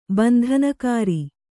♪ bandhanakāri